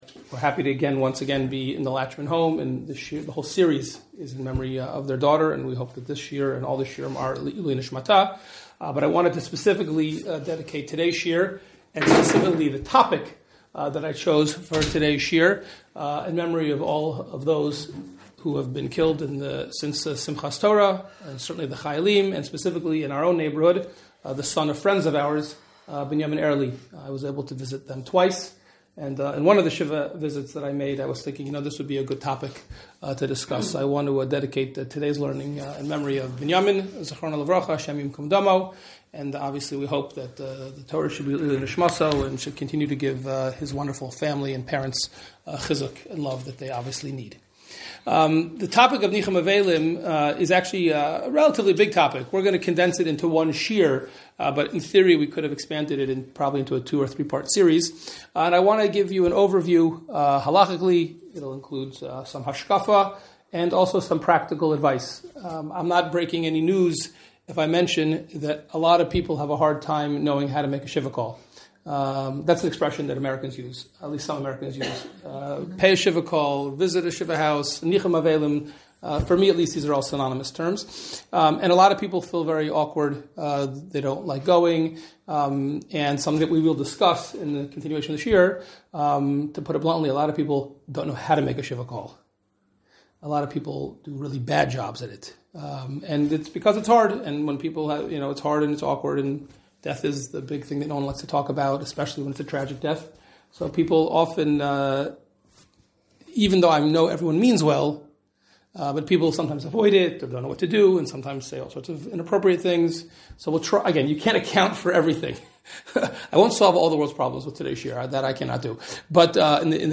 An halakhic shiur given on Nov 22, 2023 at Kehillat HaEla (Ramat Beit Shemesh).